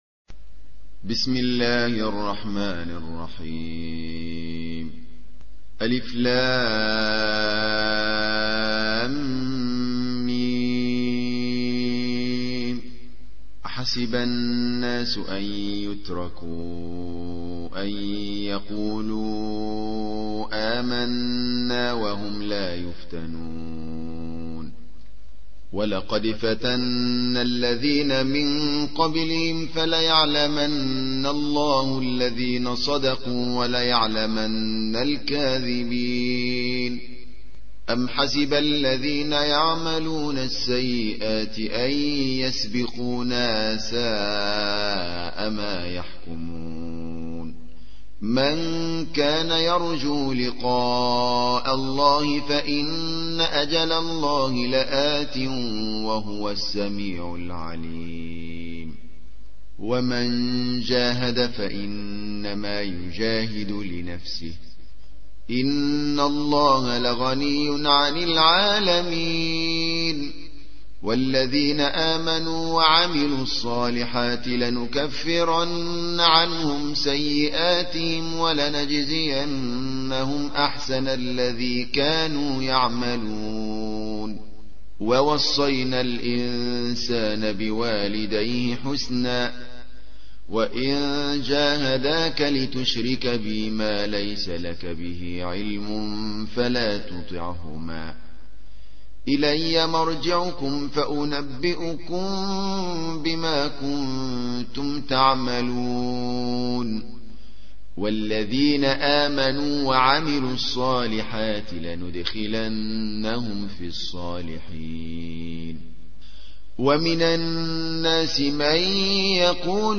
29. سورة العنكبوت / القارئ